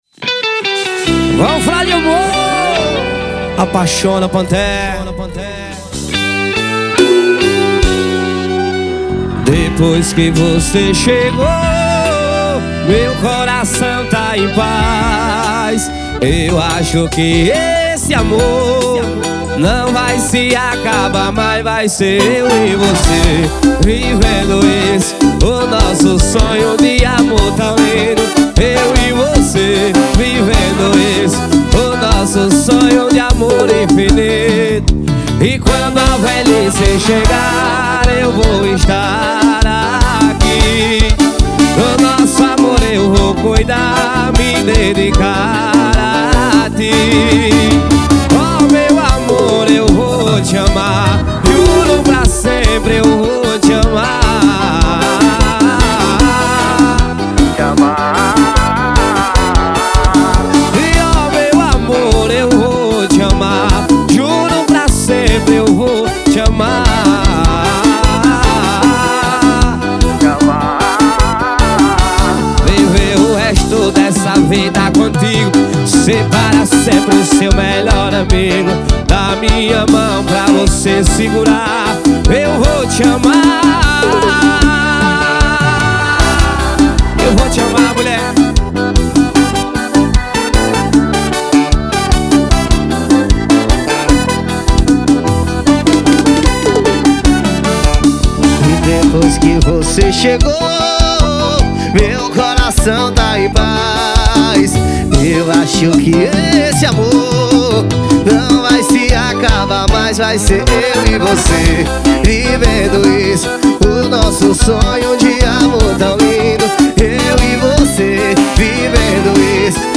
2024-02-14 18:16:49 Gênero: Forró Views